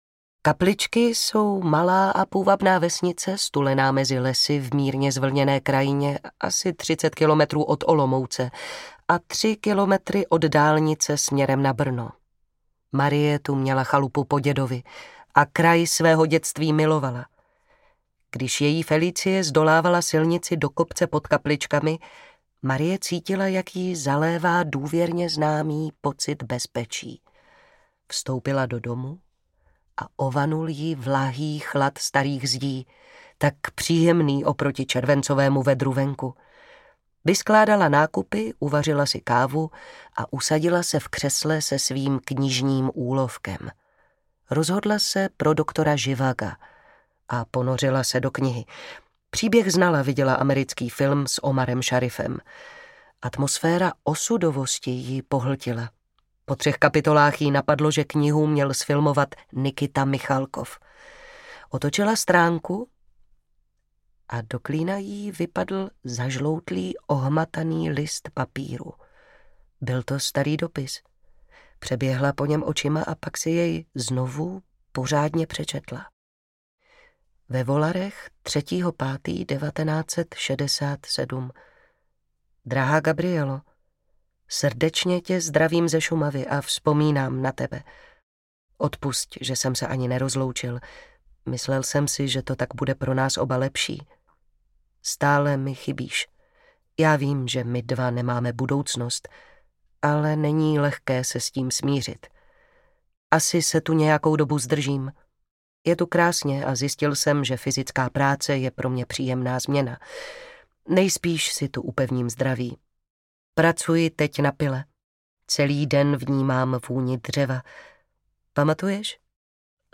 Prach, popel a dým audiokniha
Ukázka z knihy
Vyrobilo studio Soundguru.